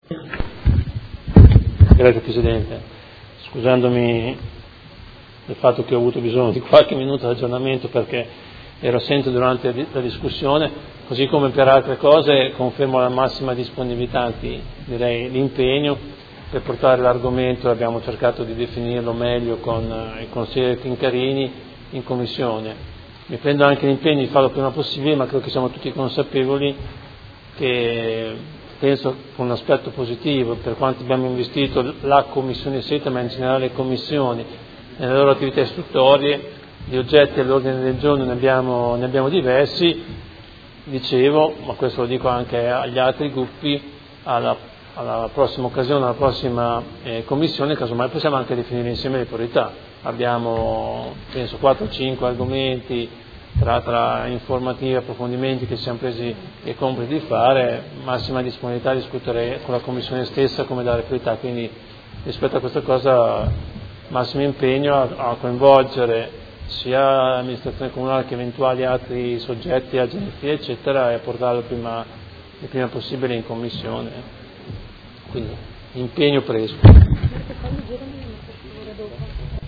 Seduta del 9/11/2017. Dibattito su Ordine del Giorno presentato dai Consiglieri Arletti, Baracchi, Bortolamasi, Pacchioni, Forghieri, Di Padova, Venturelli, Poggi, Lenzini, Fasano e De Lillo (PD) avente per oggetto: Difesa dell’Accordo internazionale di COP21 sui cambiamenti climatici (Parigi 2015)